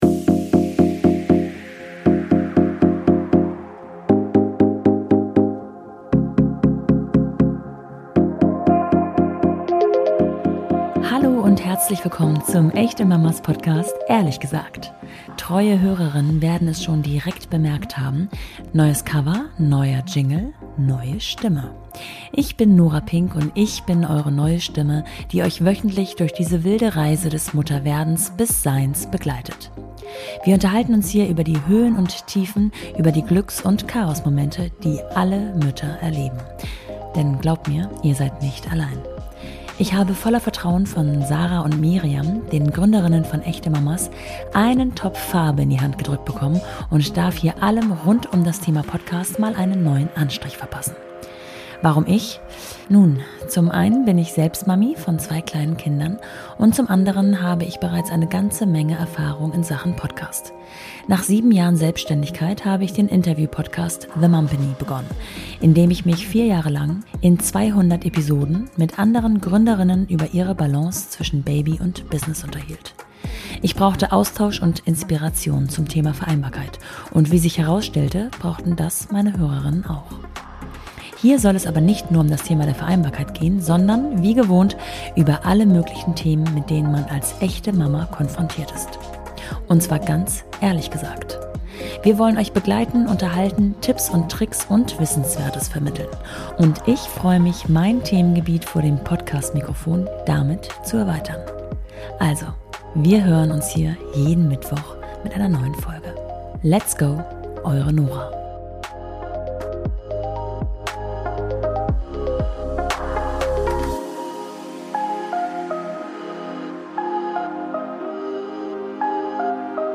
Unser Podcast bekommt einen neuen Anstrich: Neues Cover, neuer Jingle, neue Stimme.